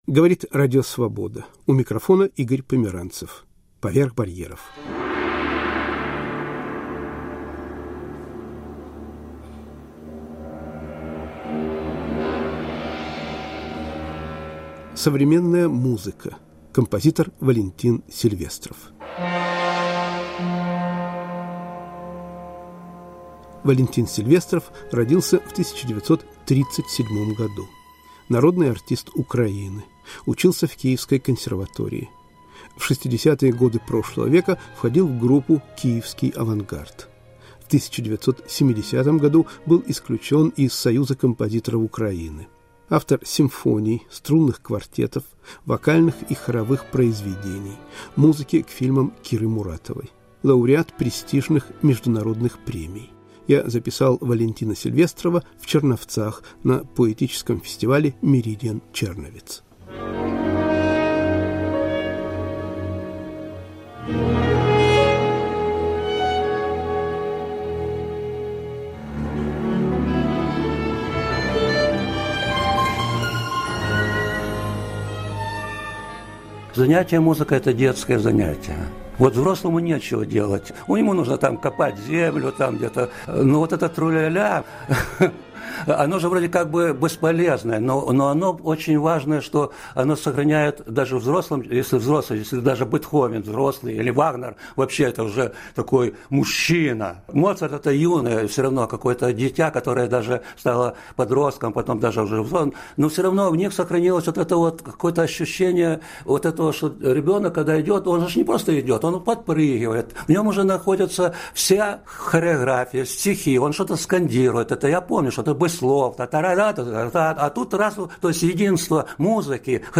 Рассказывает украинский композитор Валентин Сильвестров